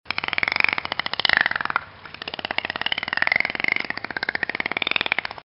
Звуки дельфинов
Слушайте онлайн или скачивайте треки в высоком качестве: игривые щелчки, мелодичные свисты и ультразвуковые волны, создающие атмосферу океанского спокойствия.
Звук стрекочущего дельфина